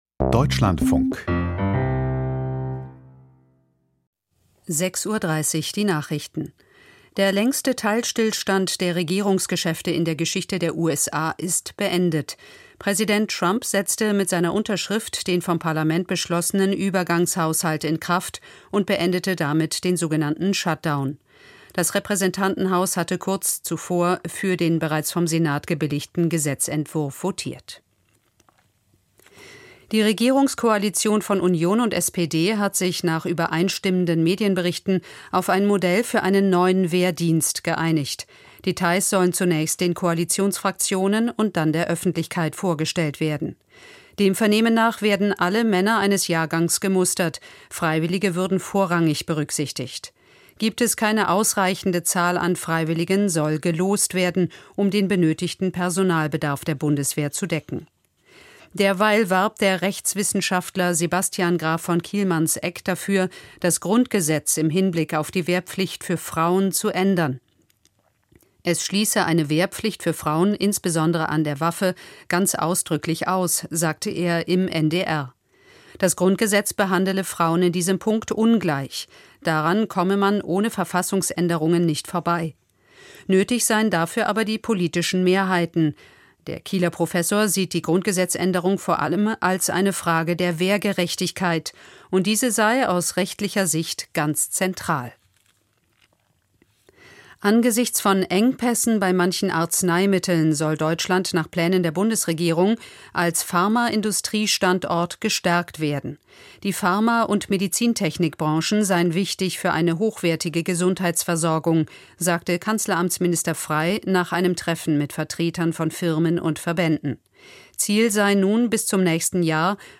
Die Nachrichten vom 13.11.2025, 06:30 Uhr
Aus der Deutschlandfunk-Nachrichtenredaktion.